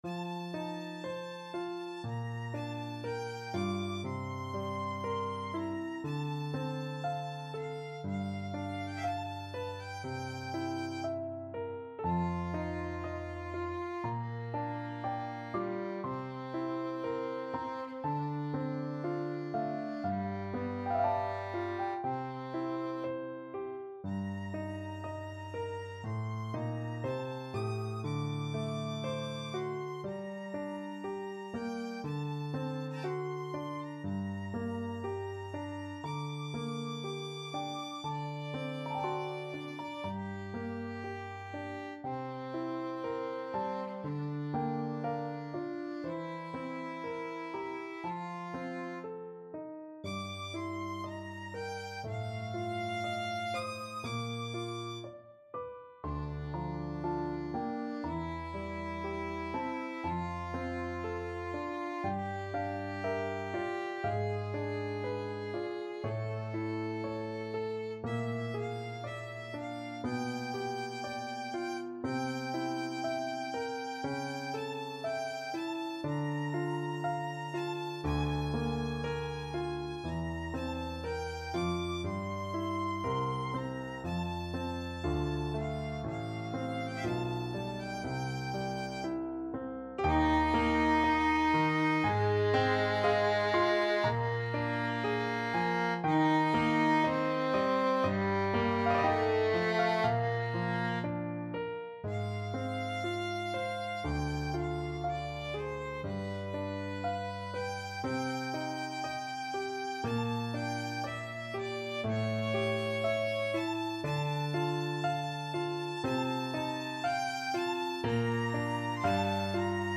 Violin version
3/2 (View more 3/2 Music)
~ = 60 Largo
Classical (View more Classical Violin Music)